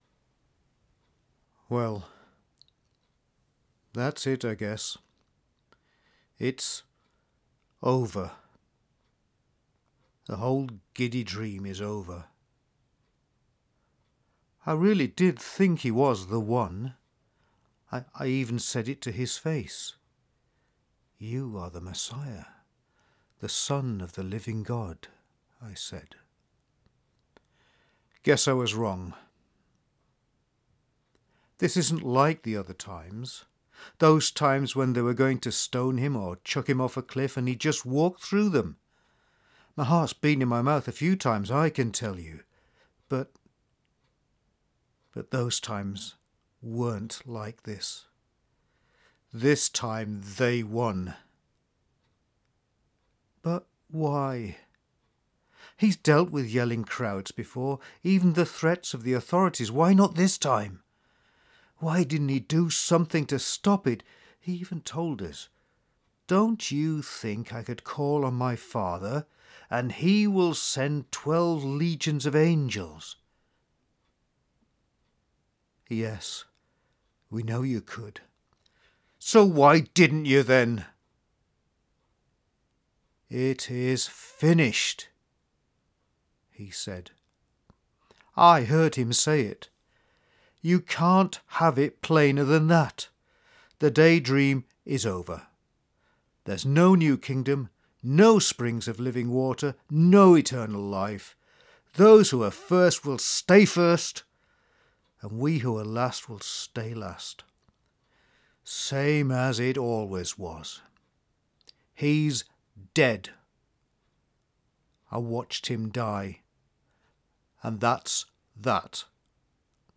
Good Friday Spoken Word – Video, MP3 and Script
Follow Peter as he walks home on a Friday evening and listen as he tries to make sense of the last few days. Hear his questions, his anger, his shattered hopes – his faith and doubt mixed together with tears.